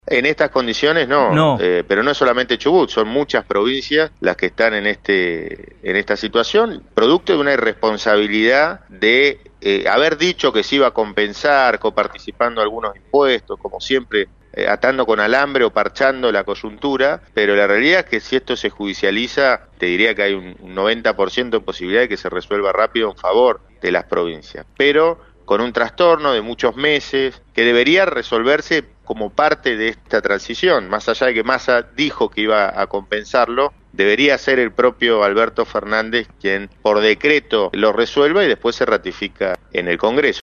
“Chubut no va a poder pagar los aguinaldos producto de la irresponsabilidad de Massa. Hay otras provincias en esta situación”, advirtió Torres en declaraciones al programa «Si pasa. pasa» por Radio Rivadavia.